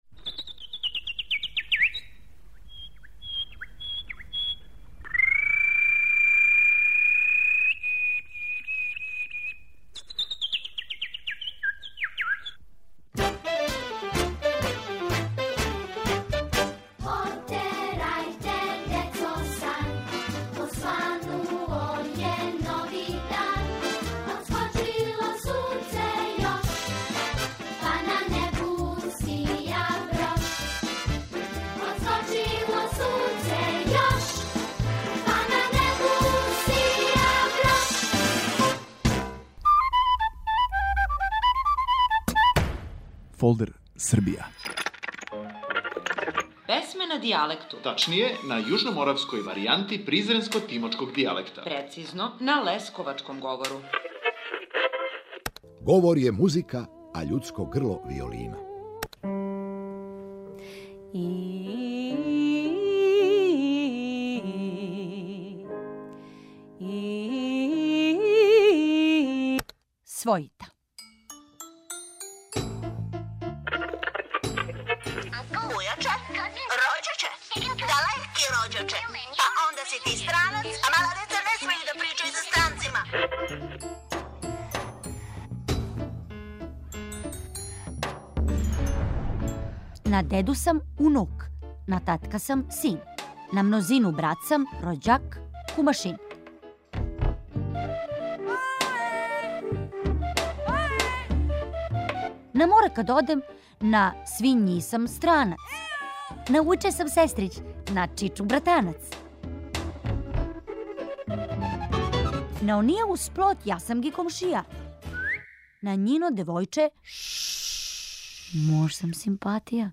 Будимо вас духовитим песмама Драгана Радовића, на јужноморавској варијанти призренско - тимочког дијалекта, прецизније - на лесковачком говору.